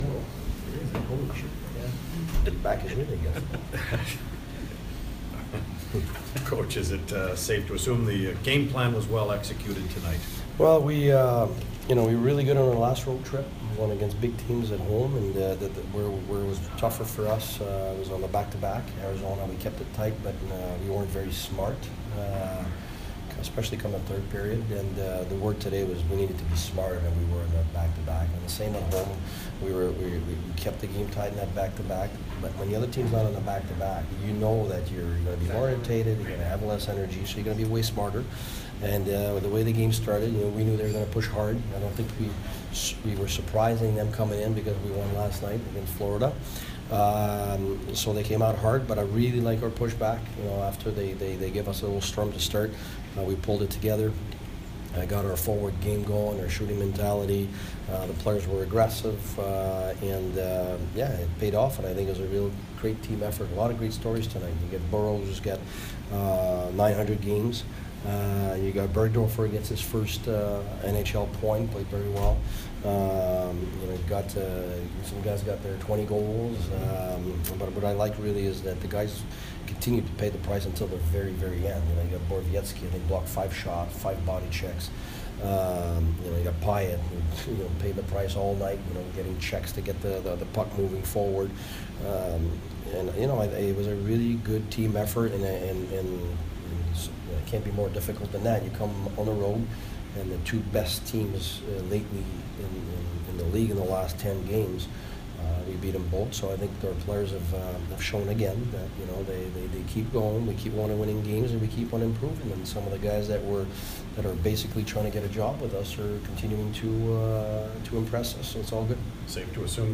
Senators Head Coach Guy Boucher post-game 3/13